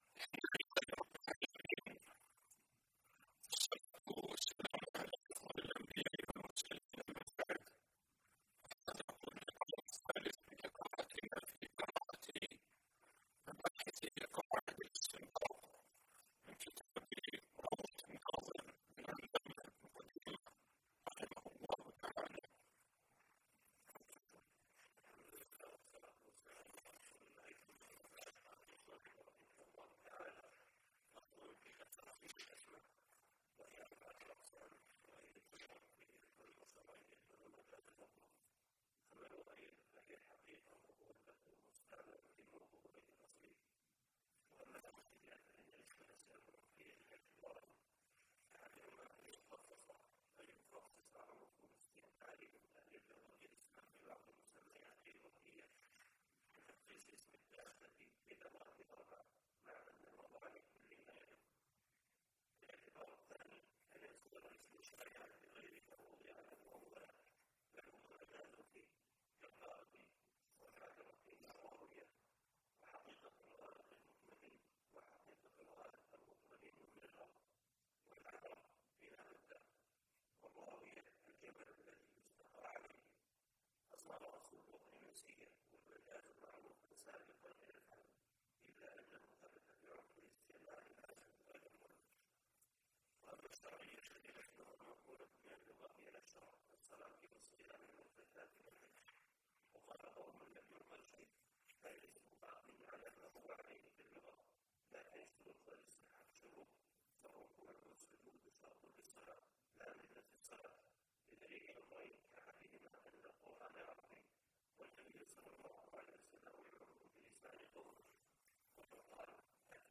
الموقع الرسمي لفضيلة الشيخ الدكتور سعد بن ناصر الشثرى | الدرس-059 فصل في تقاسيم الكلام